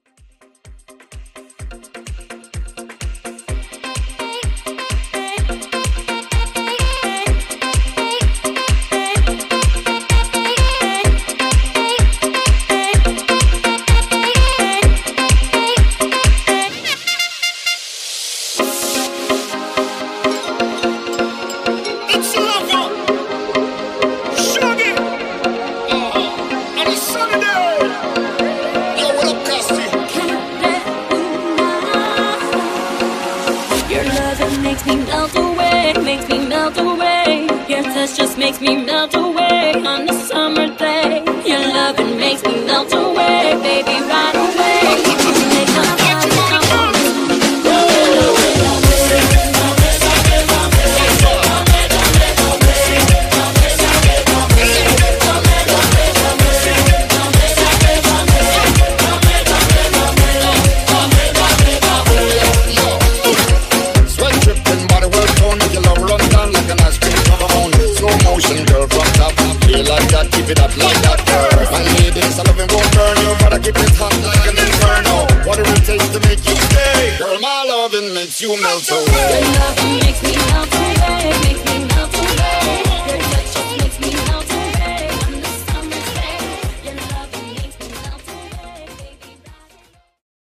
2020 Moombah Mixshow